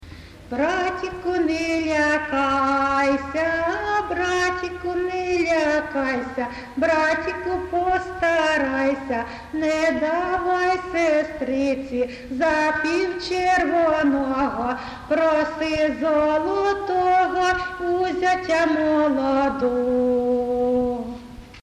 ЖанрВесільні